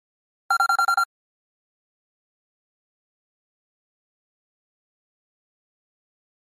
Flash Readout High Frequency Beeping